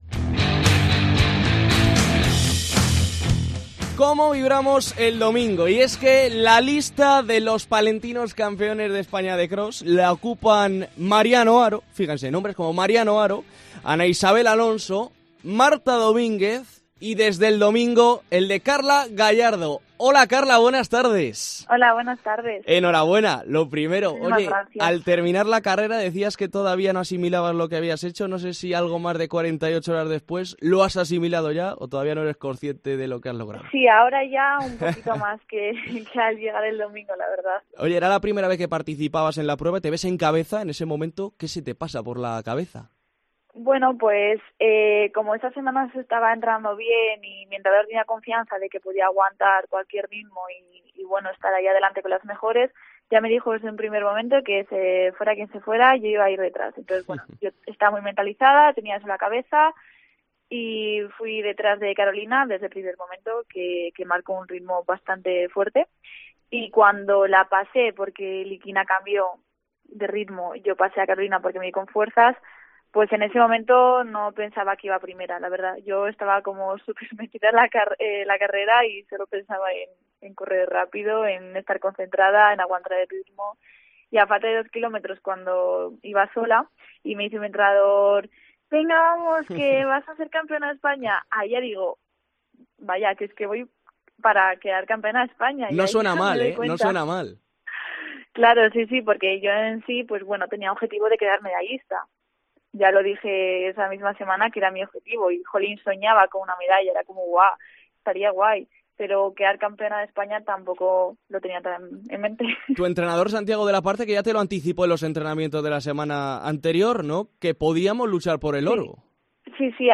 Escucha la entrevista completa en el podcast de la parte superior.